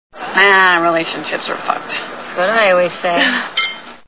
Sfx: Wine glasses tinkling.